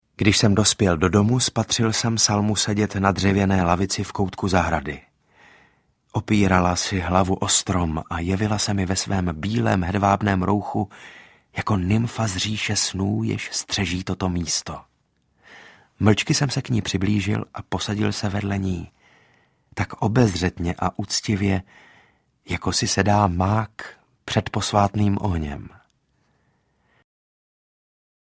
Zlomená křídla audiokniha
Ukázka z knihy
• InterpretSaša Rašilov mladší, Klára Oltová
zlomena-kridla-audiokniha